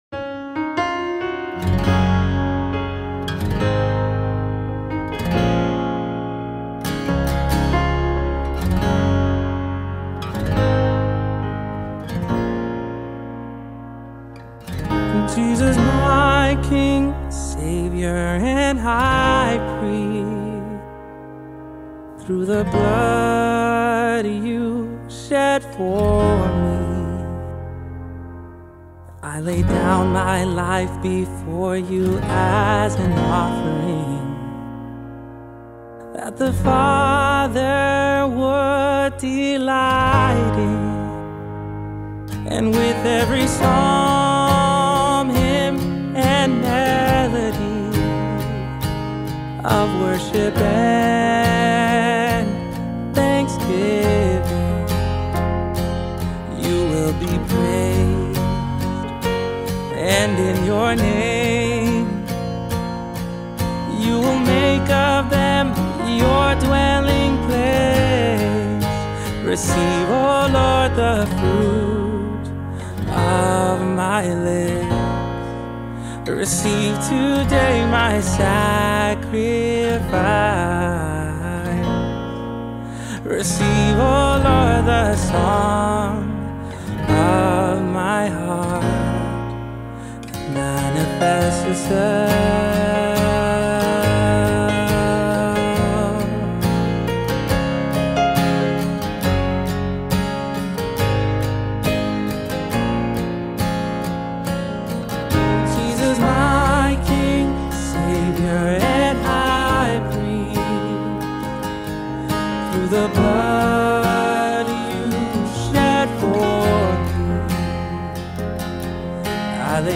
BPM: 138